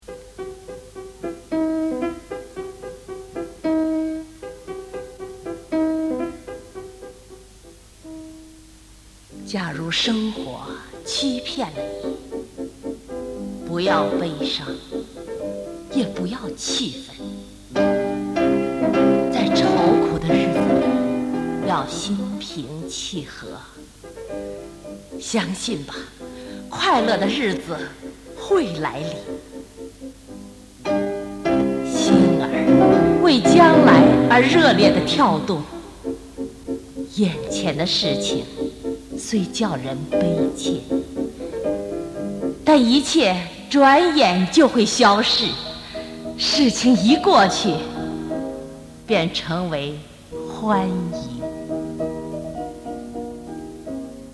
《假如生活欺骗了你》mp3朗读